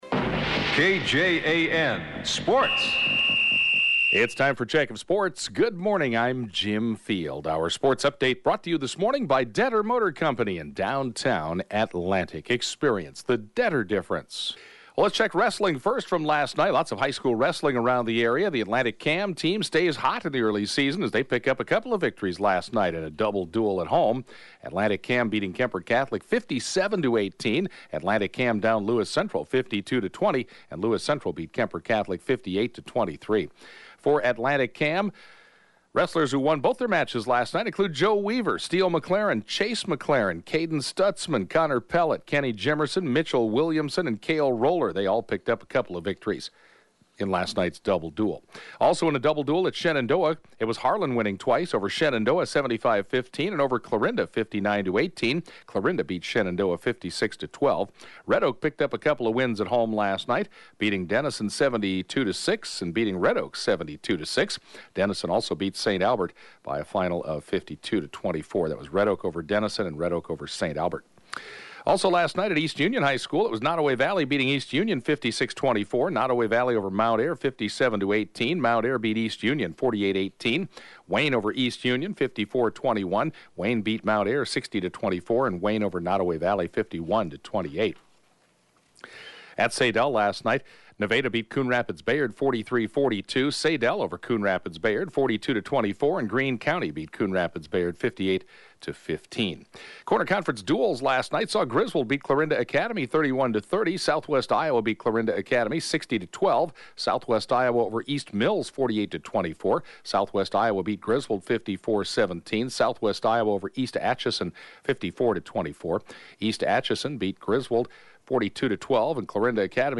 (Podcast) KJAN Morning Sports report, 3/6/2017